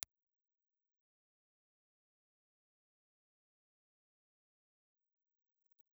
Impulse Response file of the RCA BK-5B ribbon microphone in position M
RCA_BK5_M_IR.wav
A three-position bass roll-off switch labeled M (Music), V1, and V2 allows users to tailor the microphone’s low-frequency response for different recording scenarios, aiding in proximity effect management or reducing low-end rumble.